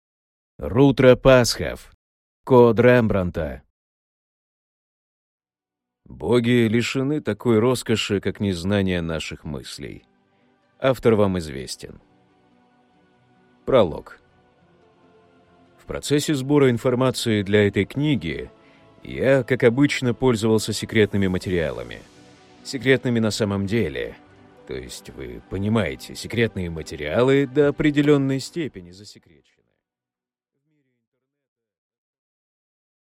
Аудиокнига Код Рембрандта | Библиотека аудиокниг